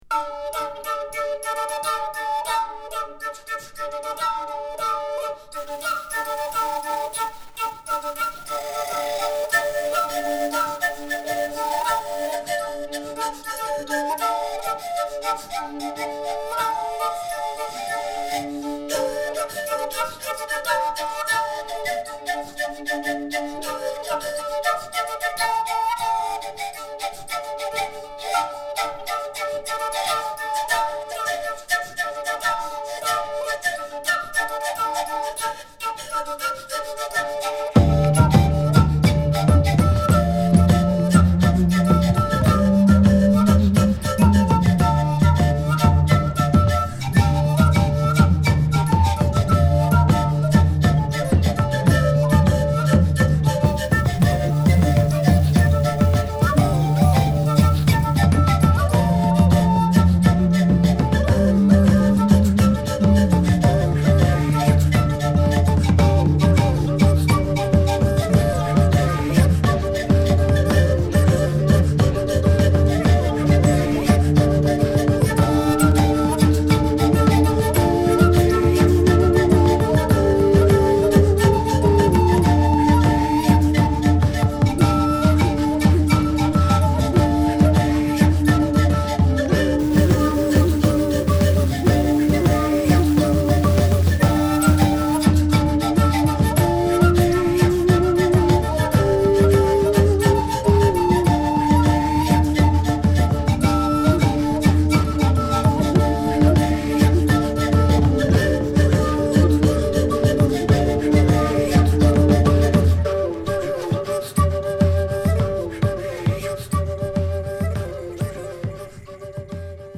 南米の民族音楽や宗教音源とフォルクローレやデジタルクンビア、スローモーハウスをミックスした独自のサウンドで
＊試聴はA1→A2→B1→C1です。